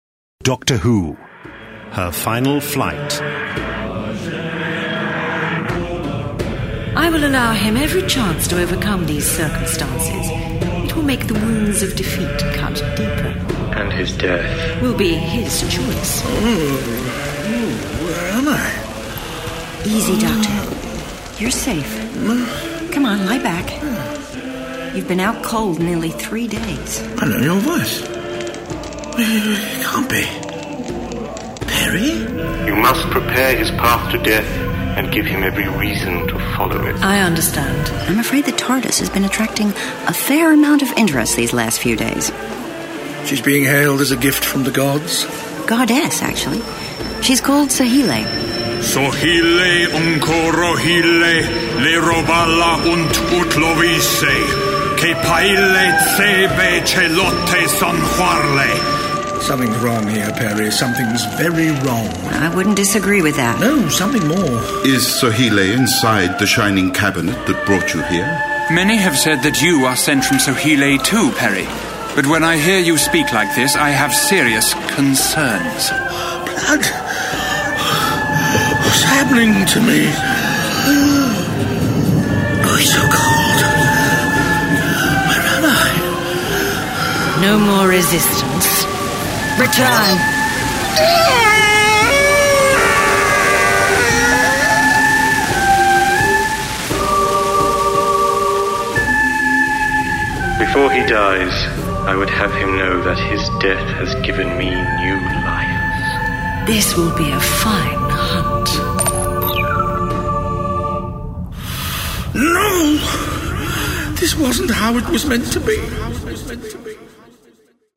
Award-winning, full-cast original audio dramas from the worlds of Doctor Who